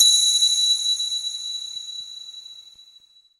Church Bells, Close, A